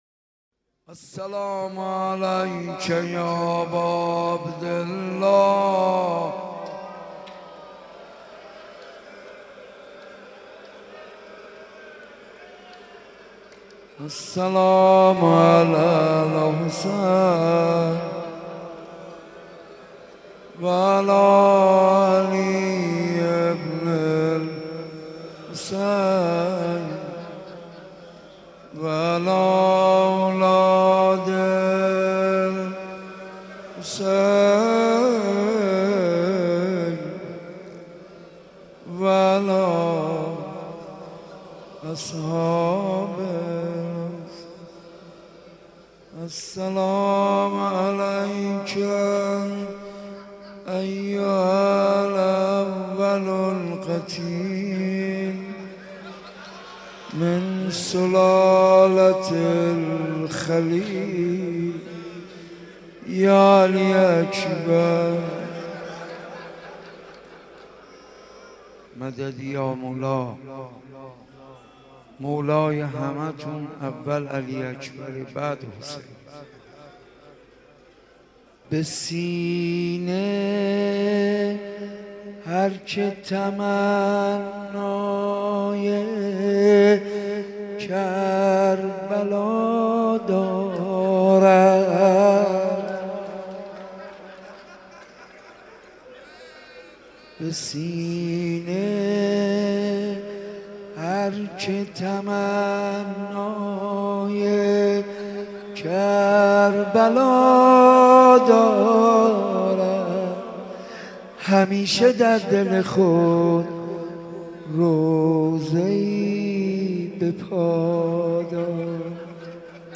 مناسبت : شب هشتم محرم
مداح : حاج منصور ارضی قالب : روضه